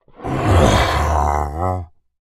Minecraft Version Minecraft Version snapshot Latest Release | Latest Snapshot snapshot / assets / minecraft / sounds / mob / ravager / idle6.ogg Compare With Compare With Latest Release | Latest Snapshot